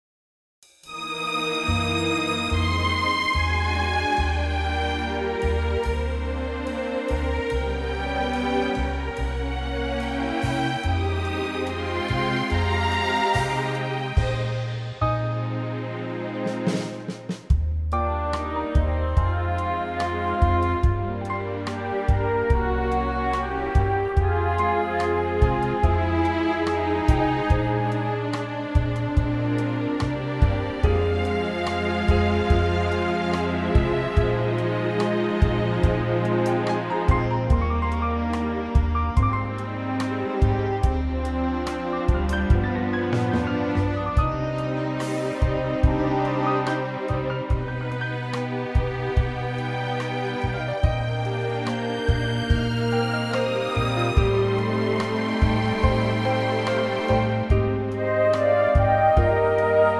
key - Eb - vocal range - G to Bb
Very lovely orchestral arrangement